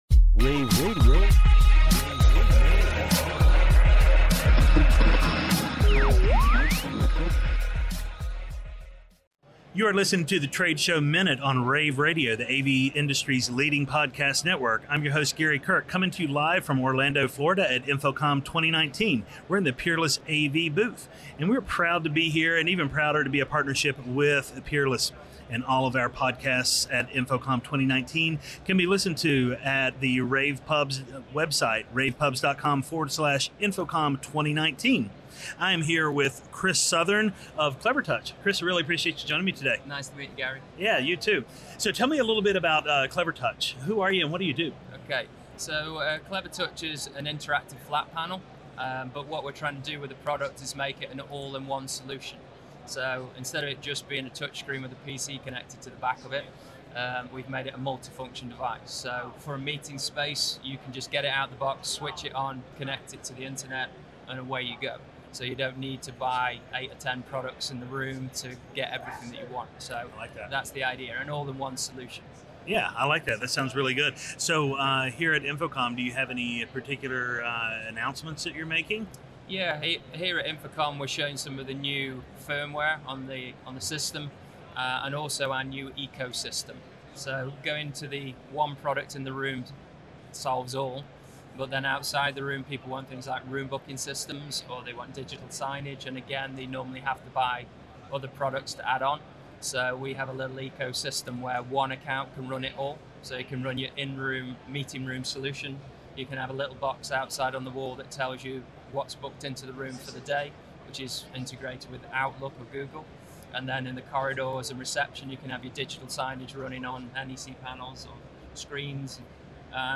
June 13, 2019 - InfoComm, InfoComm Radio, Radio, The Trade Show Minute,